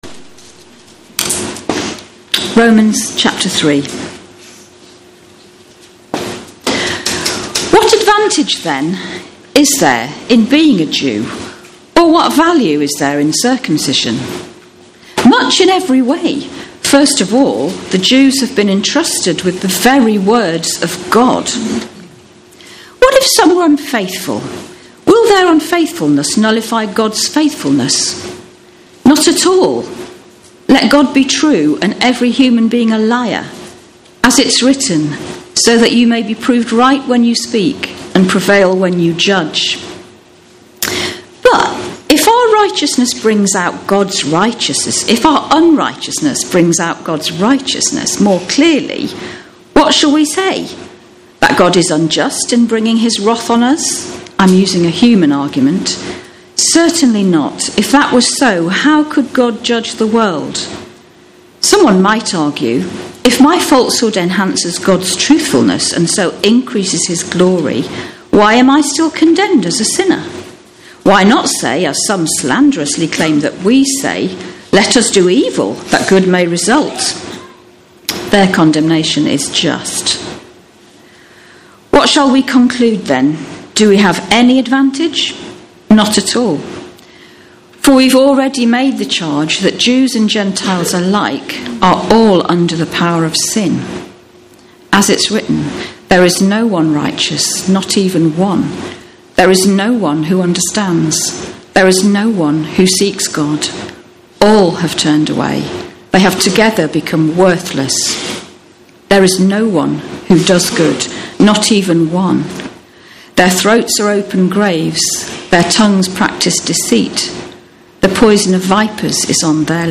We’re continuing a new series at the 10am service in the book of Romans - ‘What is a Christian’